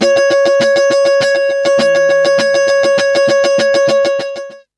100FUNKY10-R.wav